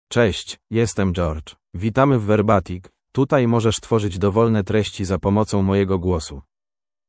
MalePolish (Poland)
GeorgeMale Polish AI voice
Voice sample
Male
George delivers clear pronunciation with authentic Poland Polish intonation, making your content sound professionally produced.